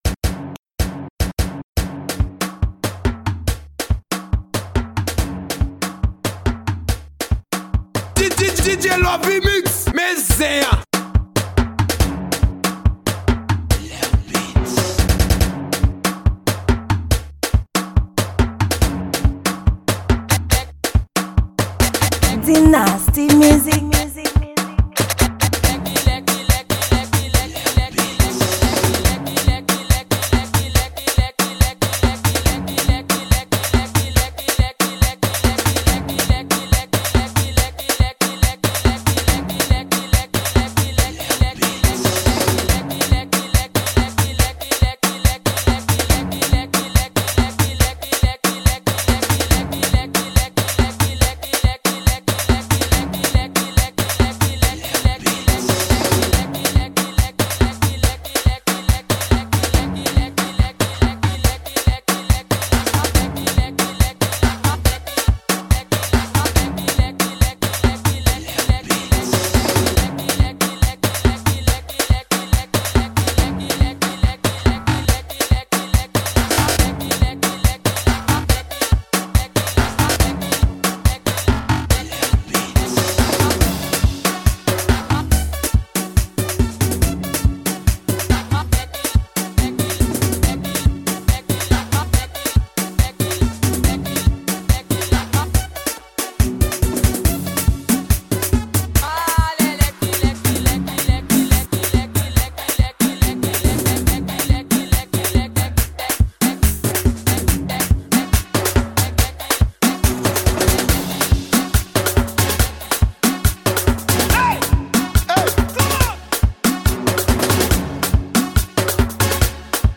Genre: Afro.